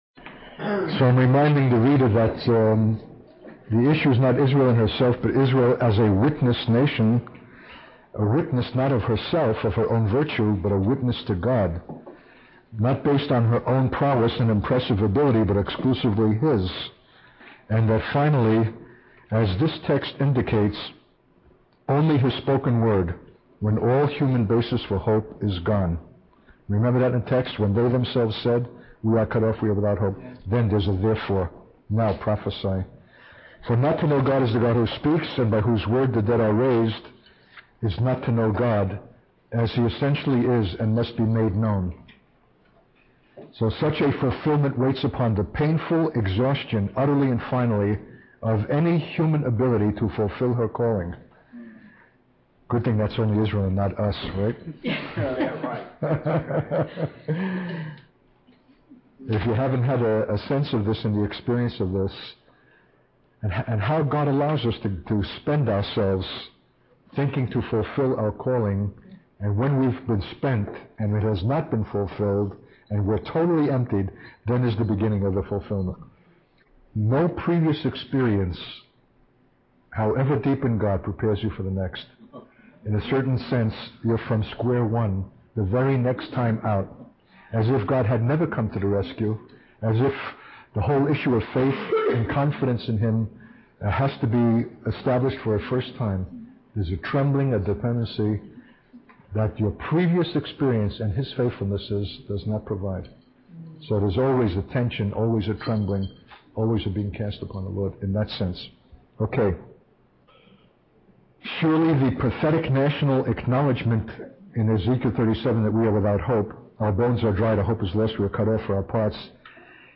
In this sermon, the speaker shares his personal experience of being reproached for going over the allotted time for his message.